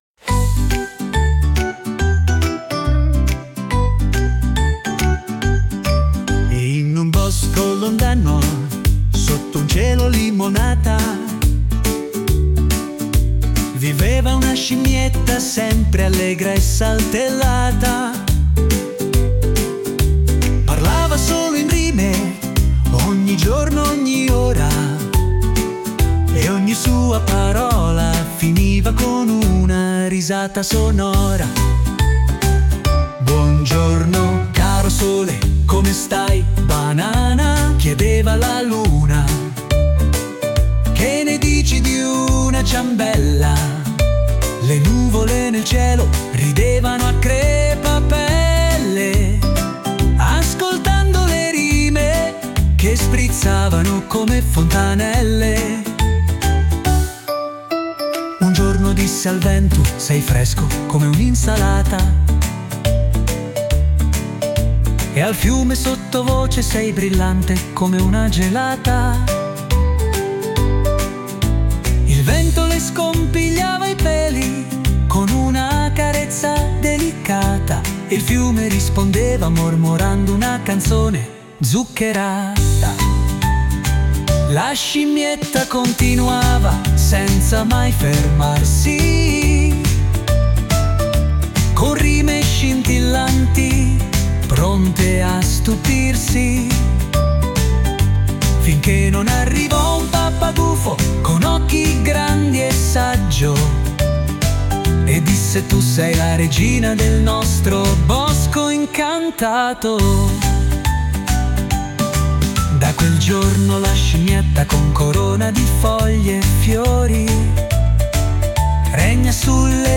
Scopri la Filastrocca della scimmia che ogni frase che dice finisce sempre con una parola che fa sorridere
filastrocca-scimmietta.mp3